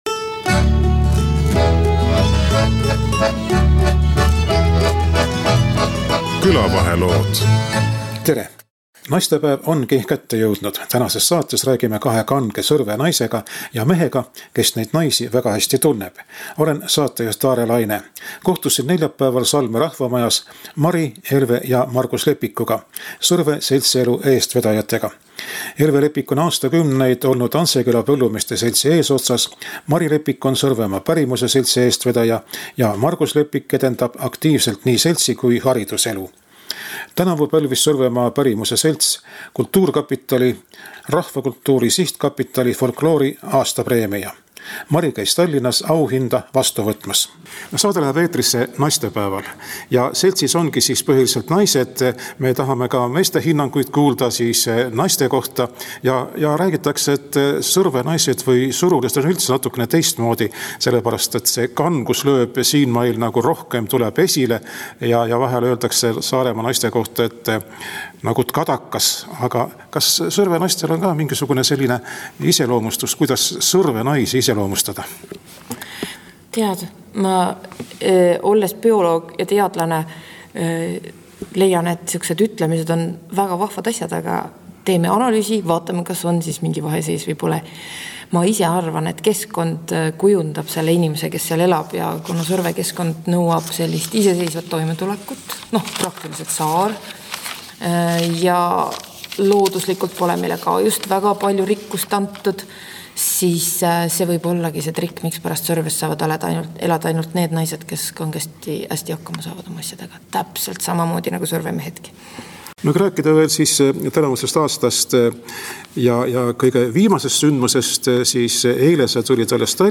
kahe kange Sõrve naisega ja mehega, kes neid hästi tunneb